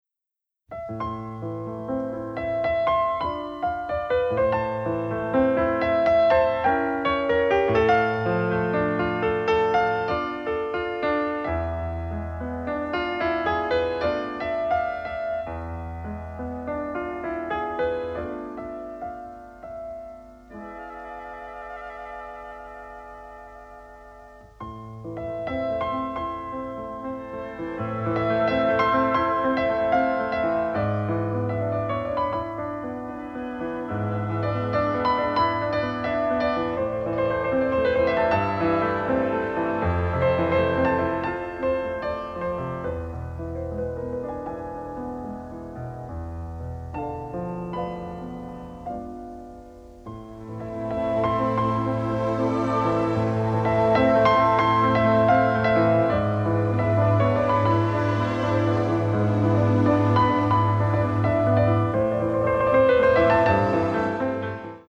and the resulting sound quality is very much improved.